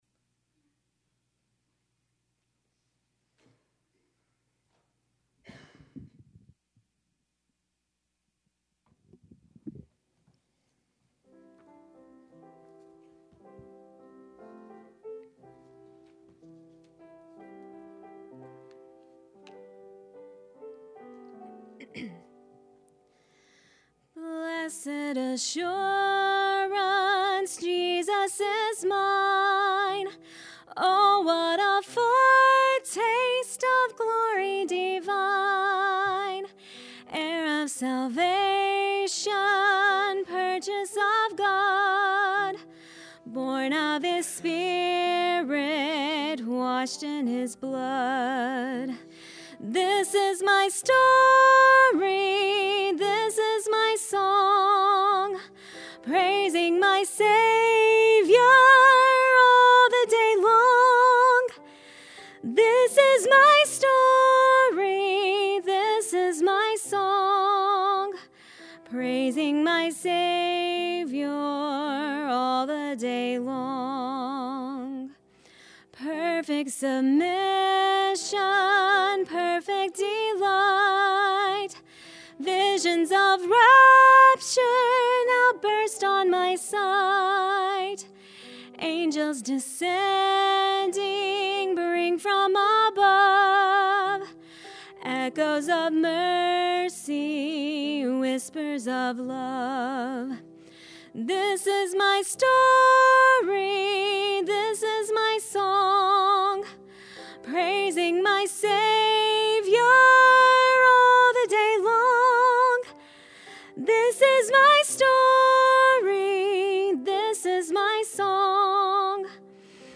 Sermons Share http